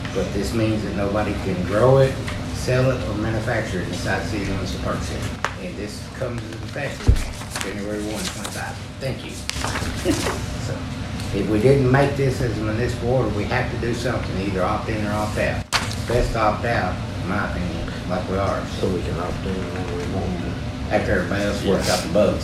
Park City Mayor, Larry Poteet, explained the city’s decision on the matter.
poteet-on-opting-out.mp3